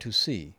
I had been experimenting with simple repairs for spot-fixing extreme sibilance/whistle. The roll-off curves suggested above can work, but you have to analyze the area and really limit the repair to the painful spot - which usually starts a fraction after the first “s” sound and can continue as a kind of echo distortion for a fraction afterwards.